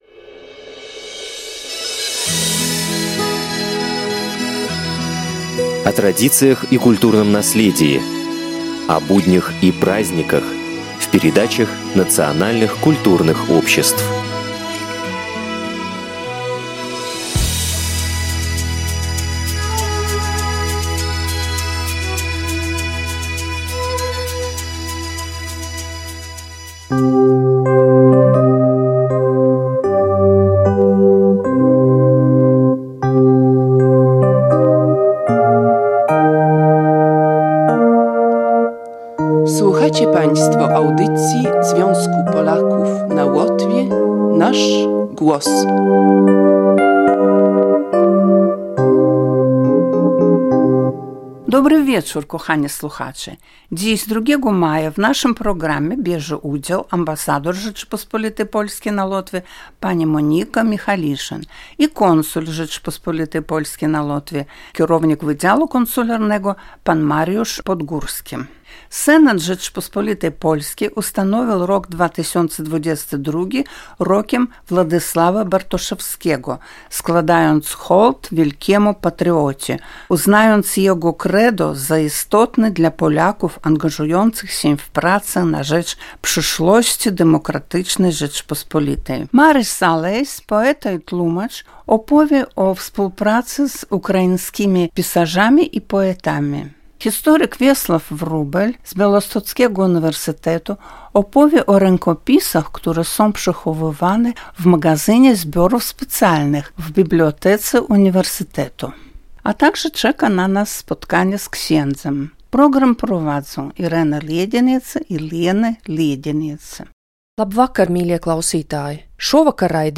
W przeddzień święta, upamiętniającego uchwalenie Konstytucji 3 maja w 1791 roku, w audycji " Nasz Głos" bierze udział ambasador Rzeczypospolitej Polskiej na Łotwie pani Monika Michaliszyn.